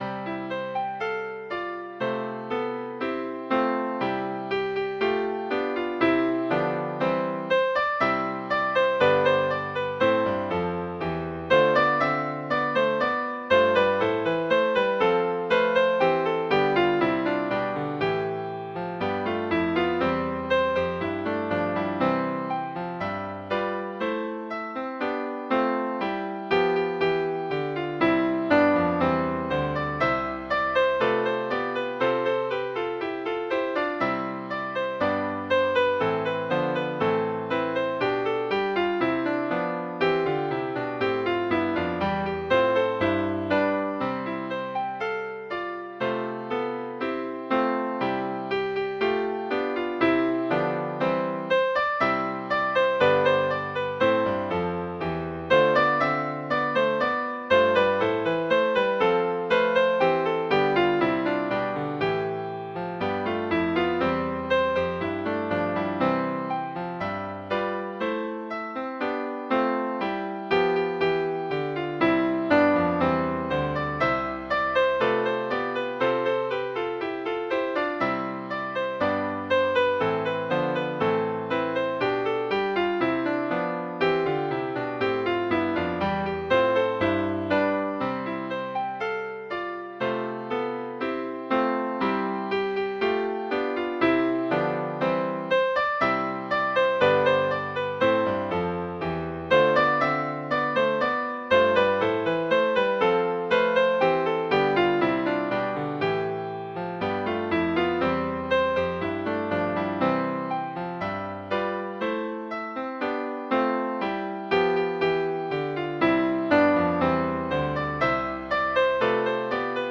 Midi File, Lyrics and Information to Lord Thomas of Winesberry